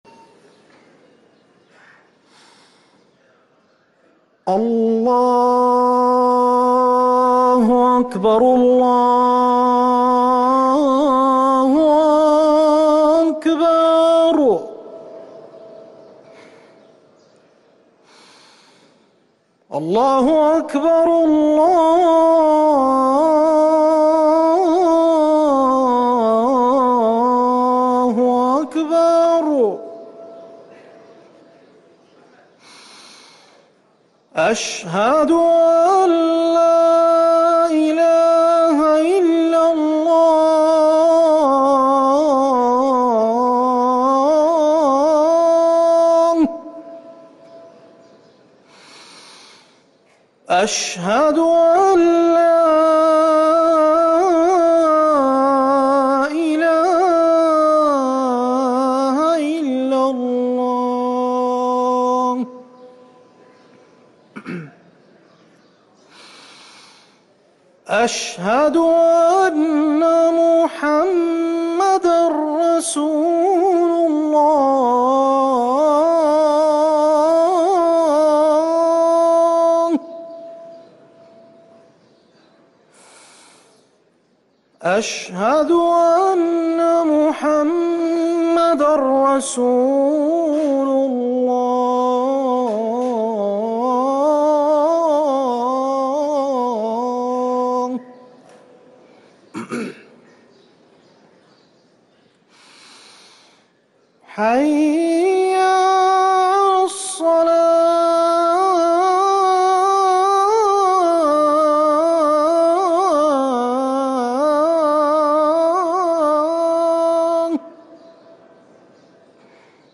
أذان الفجر للمؤذن عبدالمجيد السريحي الاثنين 28 شعبان 1444هـ > ١٤٤٤ 🕌 > ركن الأذان 🕌 > المزيد - تلاوات الحرمين